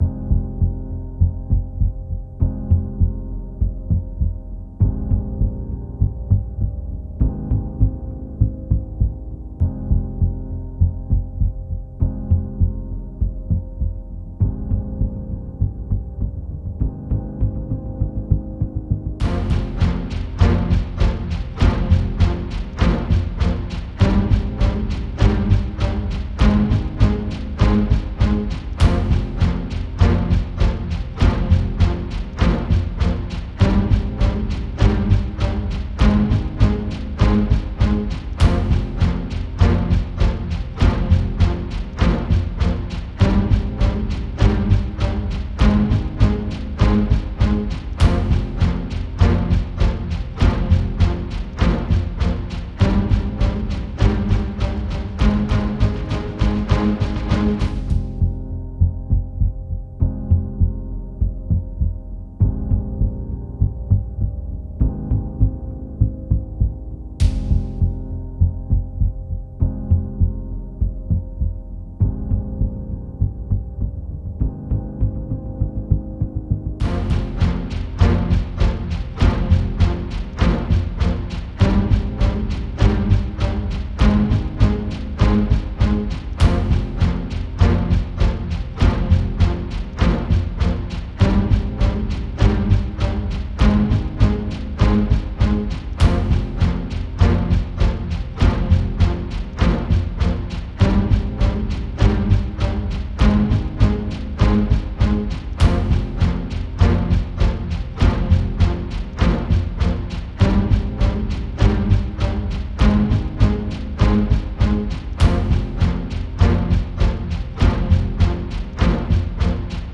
une immersion sonore sombre et oppressante